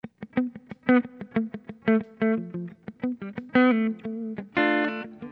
Sons et loops gratuits de guitares rythmiques 100bpm
Guitare rythmique 62